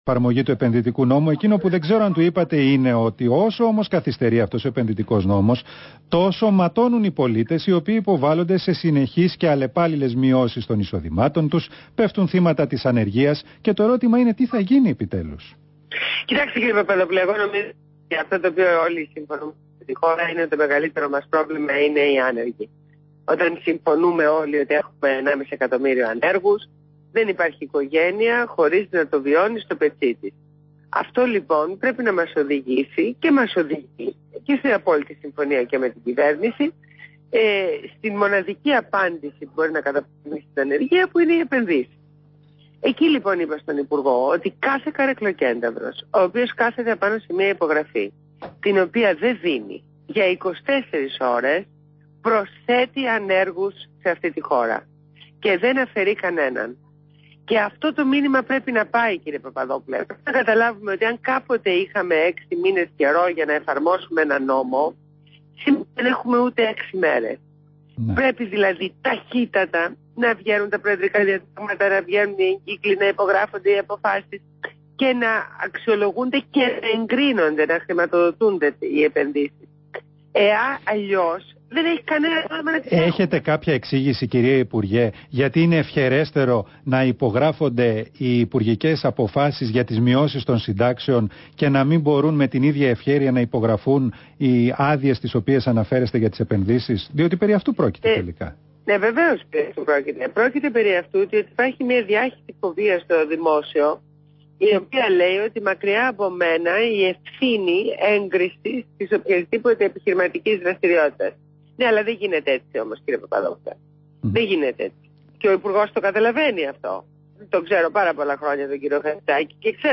Συνέντευξη στο ραδιόφωνο του REAL fm